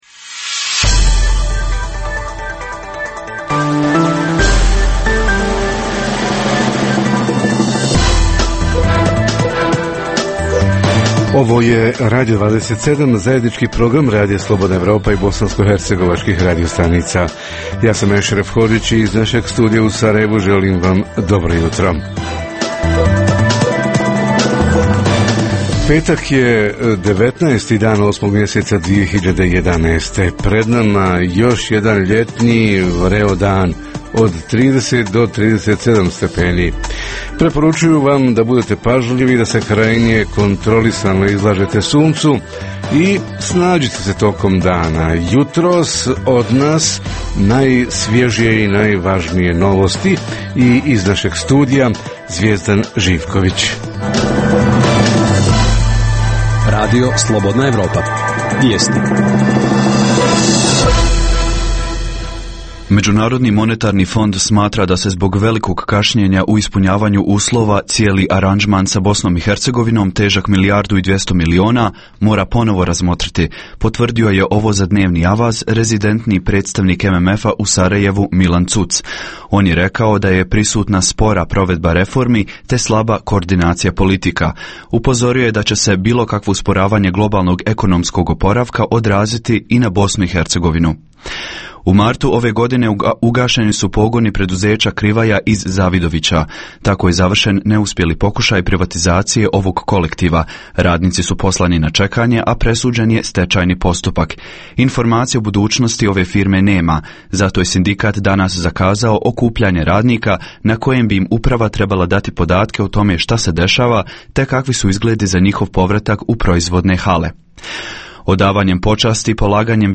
Tema jutarnjeg programa: omladinski aktivizam – ko i šta poduzima kako bi mladi bitnije uticali na svoju budućnost? Reporteri iz cijele BiH javljaju o najaktuelnijim događajima u njihovim sredinama.
Redovni sadržaji jutarnjeg programa za BiH su i vijesti i muzika.